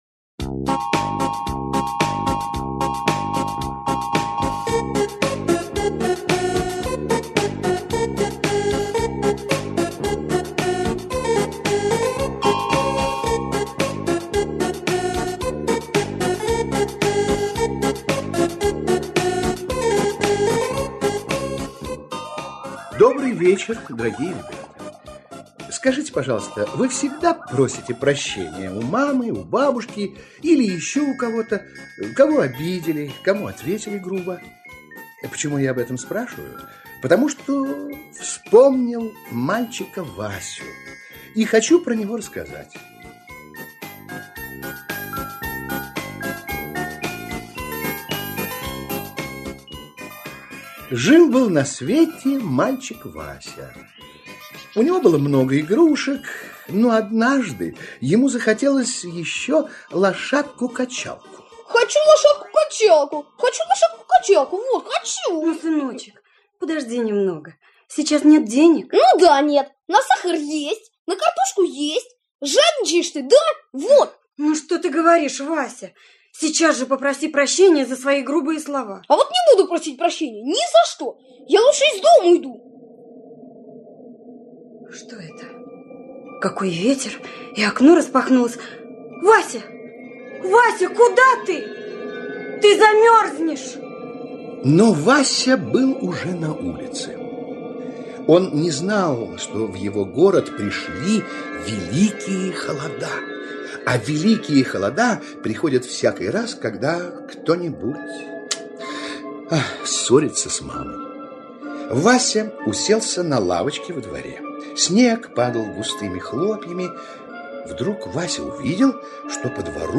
Не буду просить прощения - аудиосказка Прокофьевой - слушать